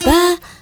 Note 5-F.wav